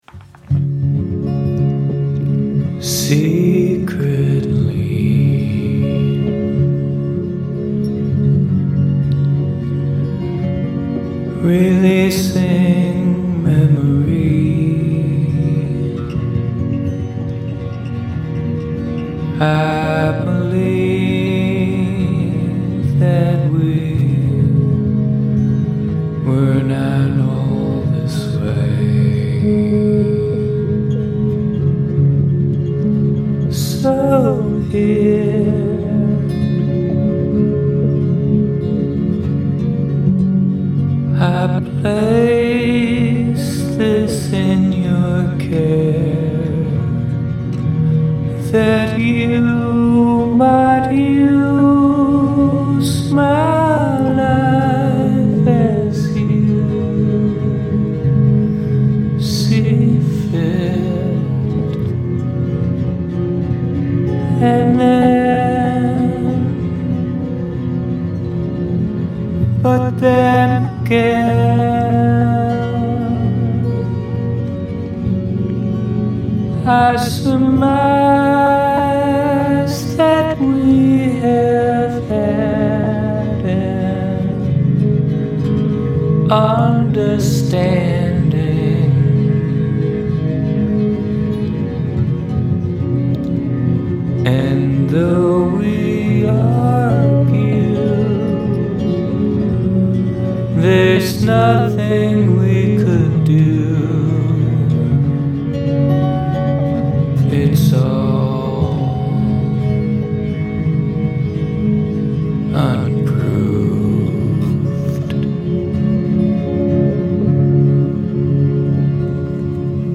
G, C, Am, D7 (repeat)